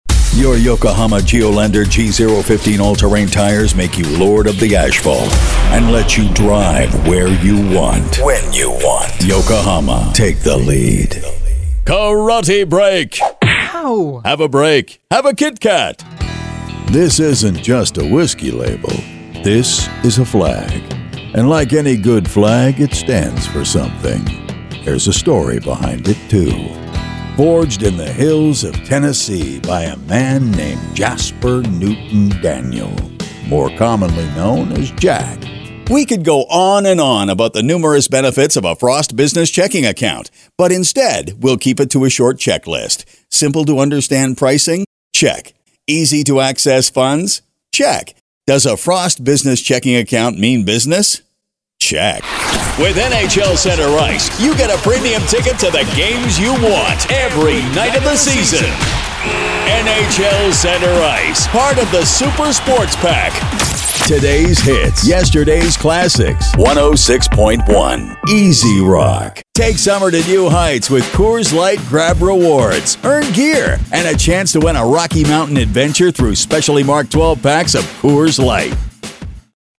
Fast, reliable and professional
Aussie, Darth Vader, Picard from Star Trek, Shakespearan
Middle Aged